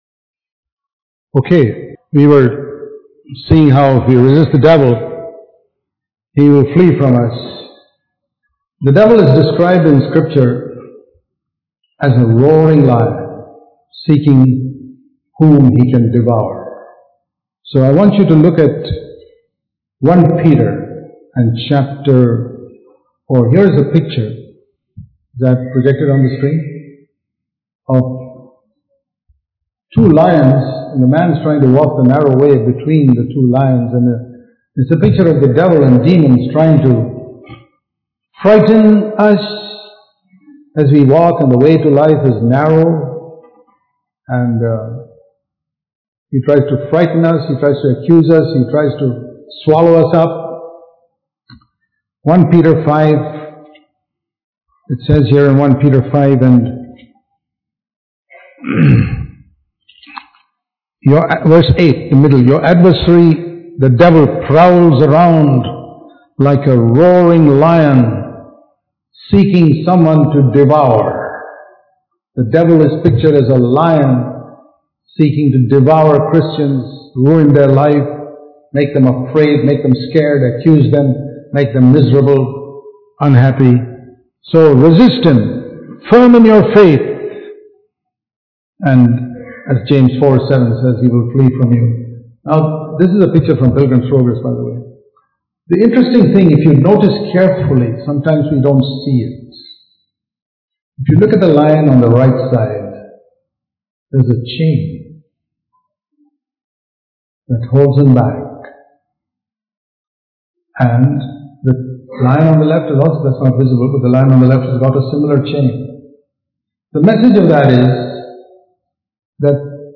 Yielding Our Will To God Dubai Special Meetings 2018